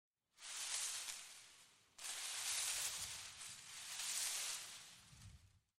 Шорох кустарника от прикосновения тела